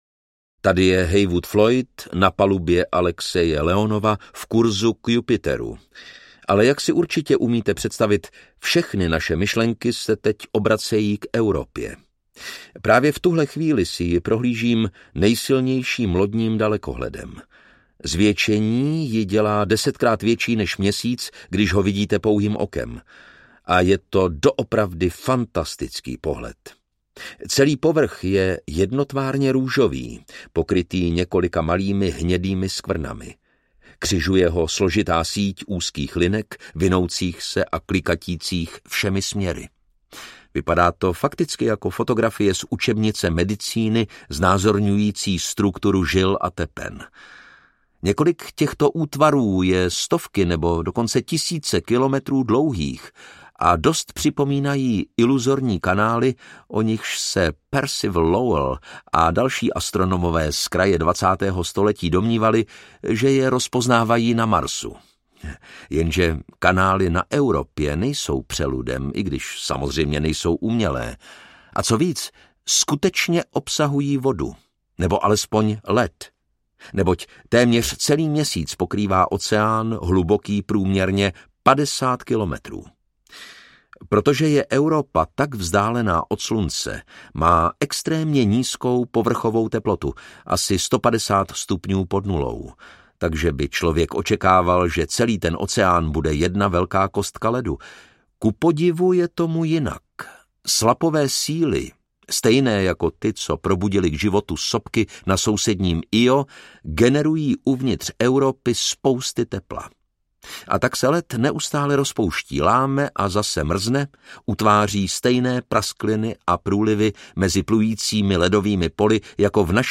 2010: Druhá vesmírná odysea audiokniha
Ukázka z knihy
Čte Lukáš Hlavica.